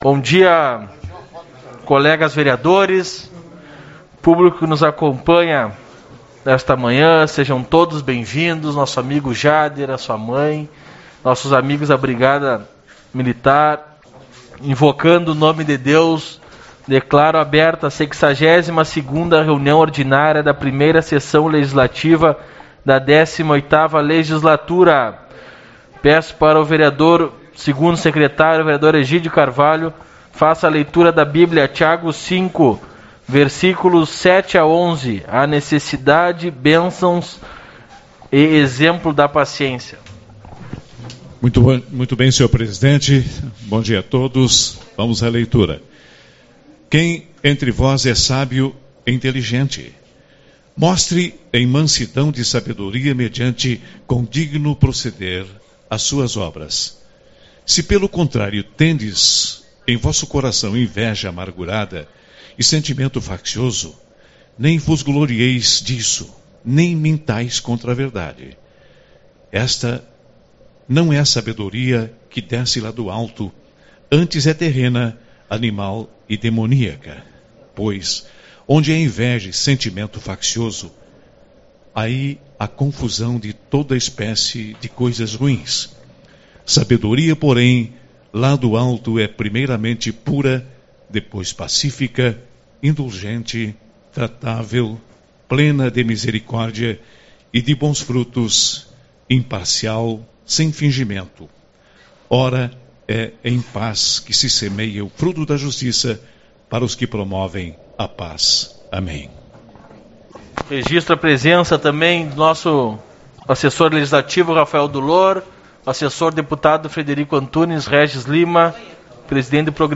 23/09 - Reunião Ordinária